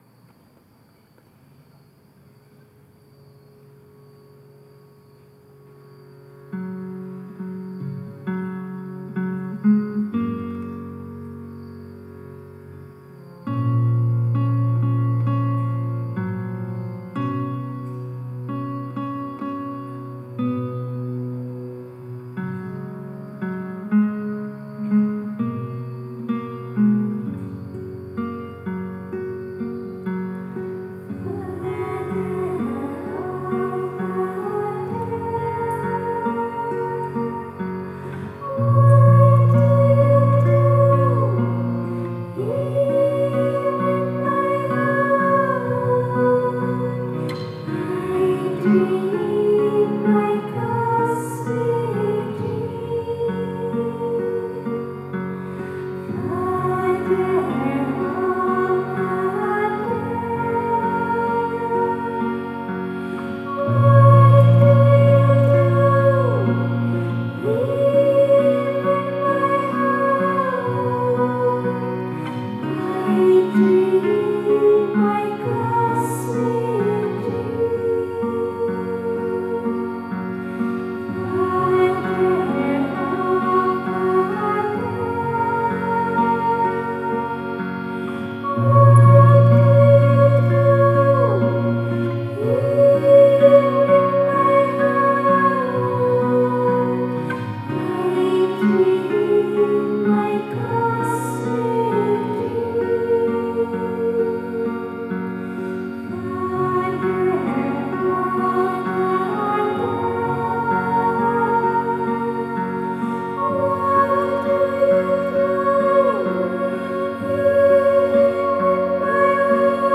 Seven soulful and meditative performances by students of Sri Chinmoy performing live, October 11 2017, on the 10th anniversary of their teacher’s passing.
These recordings are excerpted from those performances.